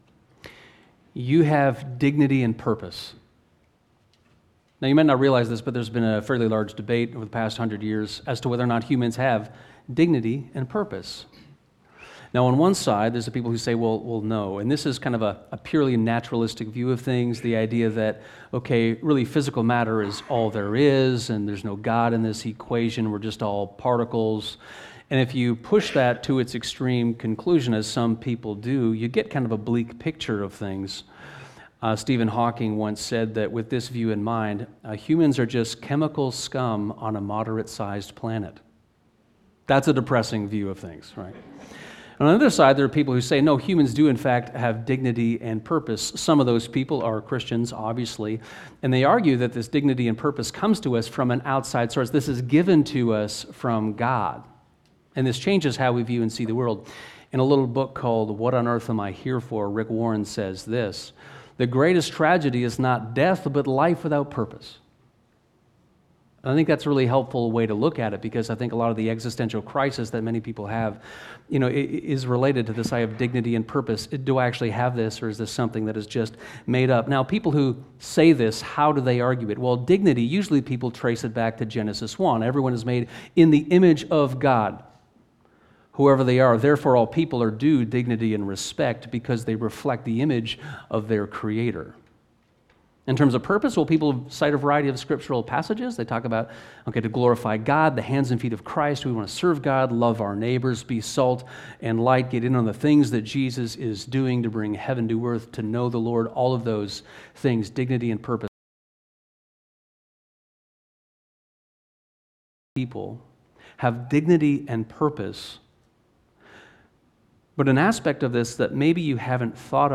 Note: During the live-stream recording of this sermon, a few short 'blackout blips' occurred.